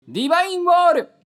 呪文 魔法 ボイス 声素材 – Magic Spell Voice